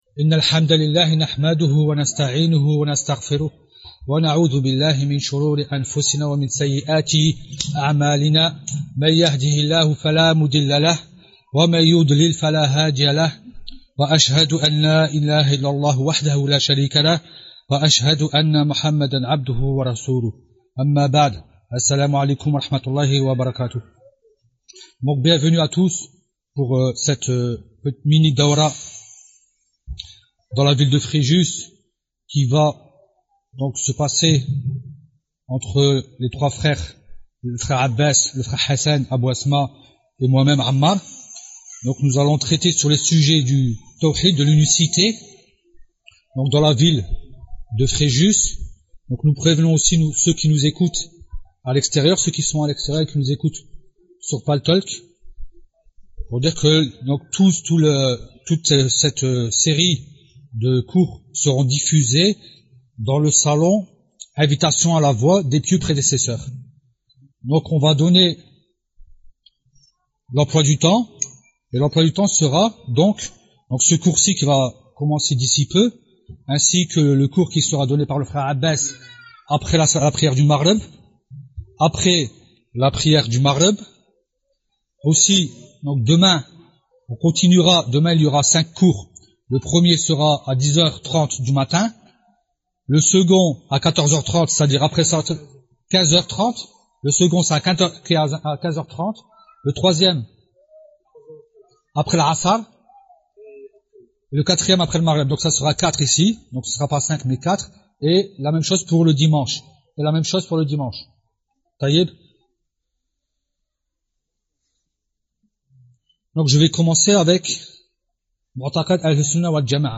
Cette belle série de huit conférences met en relief le monothéisme pur qui n’existe que dans la